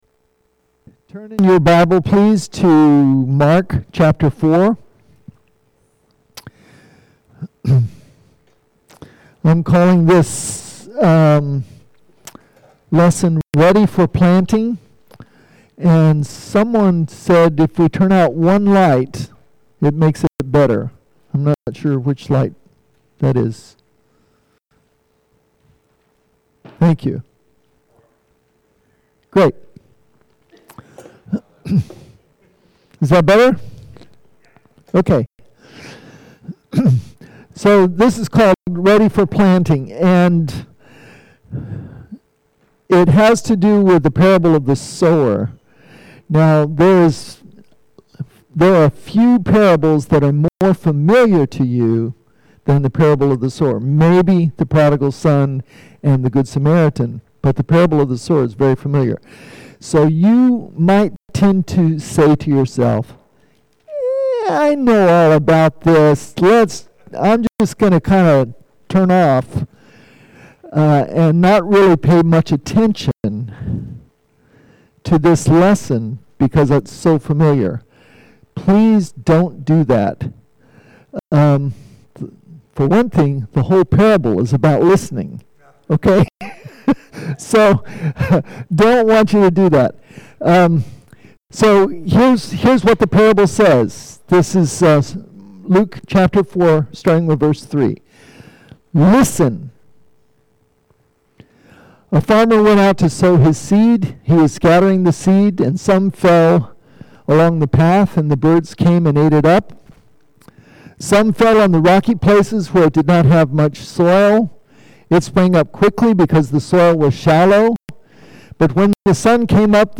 Audio Sermon